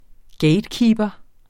Udtale [ ˈgεjdˌkiːbʌ ]